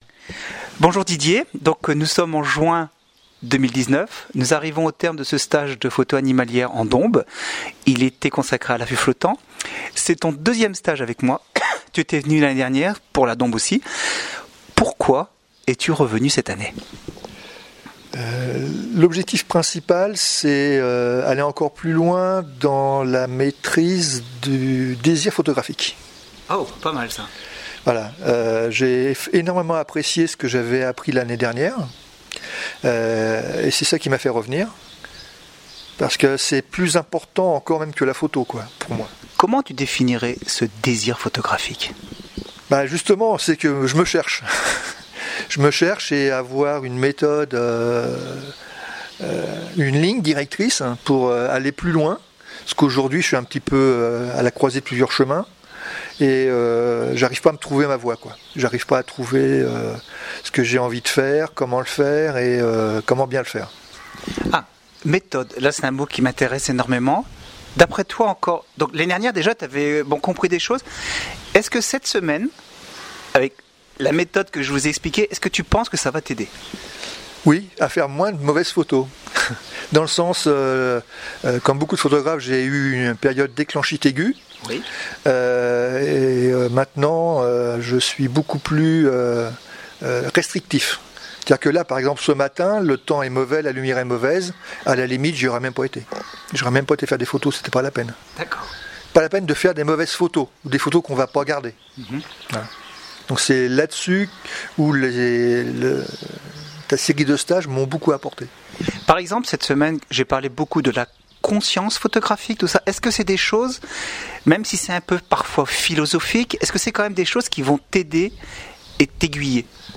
Le commentaire écrit et oral des participants